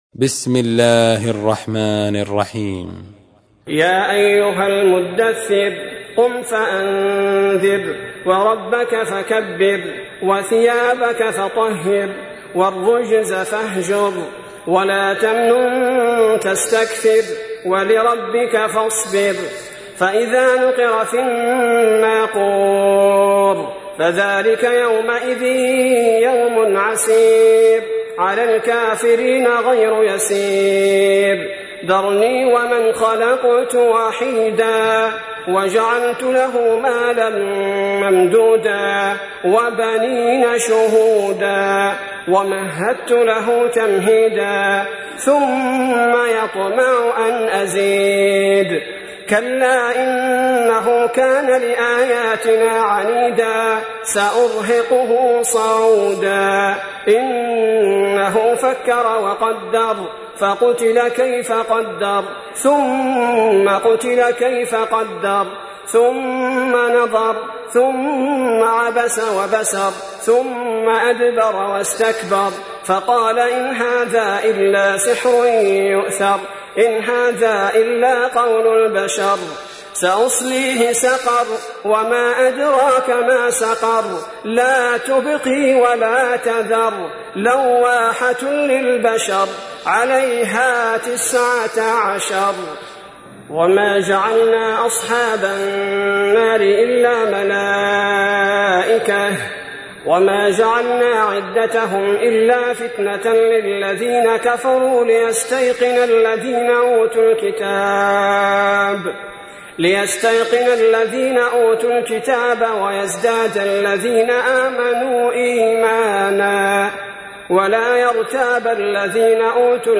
تحميل : 74. سورة المدثر / القارئ عبد البارئ الثبيتي / القرآن الكريم / موقع يا حسين